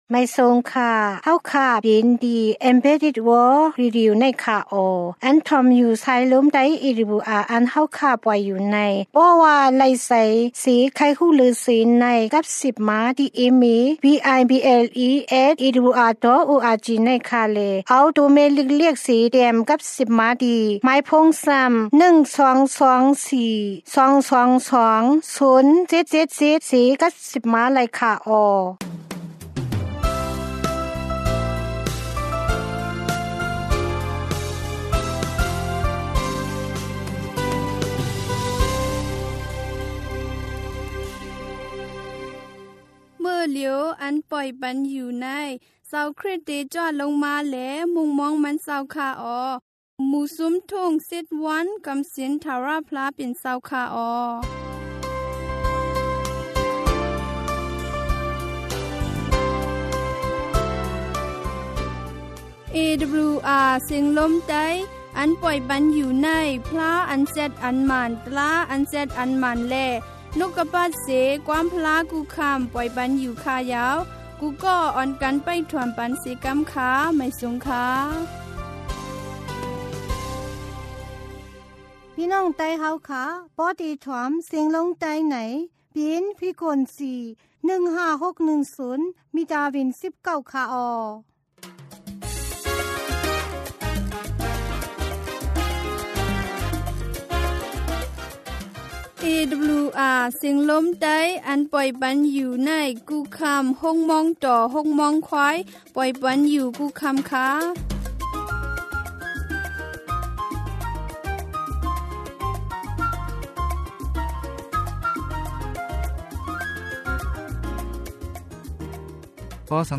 Shan hymn song,helath talk,knowledges,gospel song,Sermon.